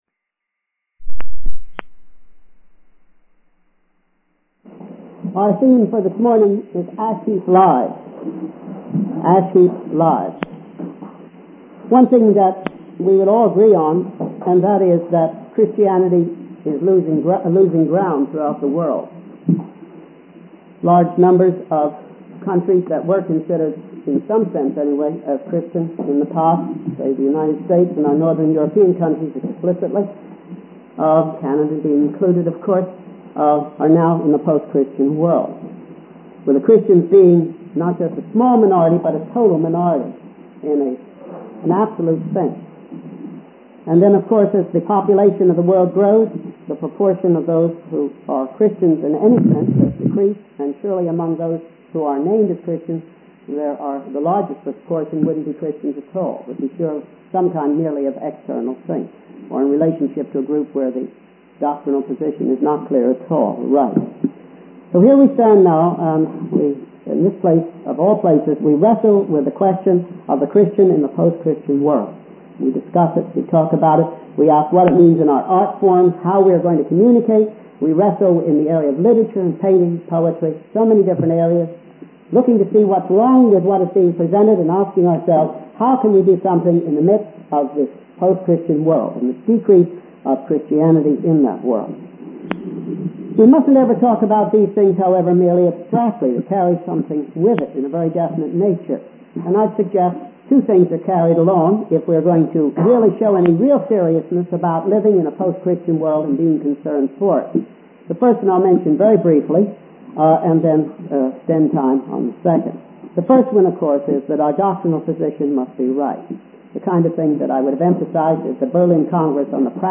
In this sermon, the speaker emphasizes the importance of viewing material possessions from a different perspective. He suggests that Christians should visit a city dump to see the things that people have spent their lives accumulating, only to realize that they hold no true value. The speaker refers to a parable in Luke 12:15-21, where Jesus warns against covetousness and emphasizes that life does not consist of the abundance of possessions.